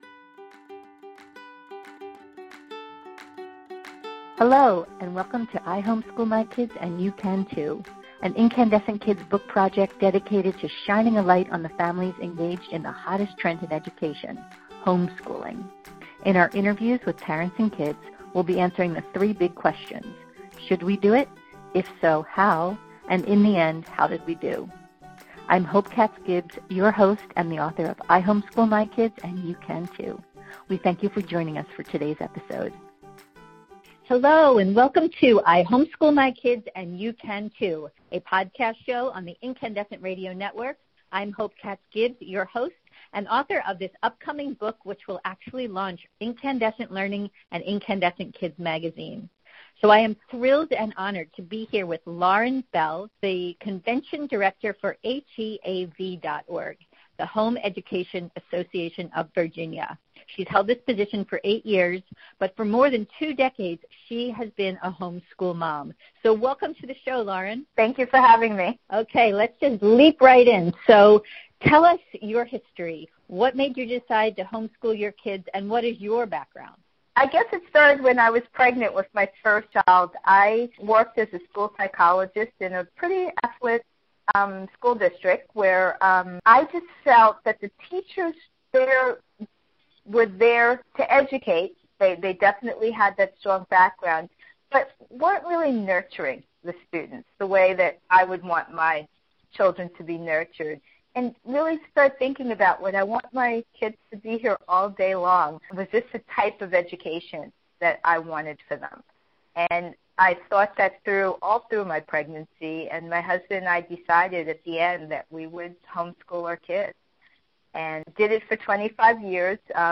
Be sure to download our podcast interview! About the show: From the mouths of babes come the most profound thoughts and ideas.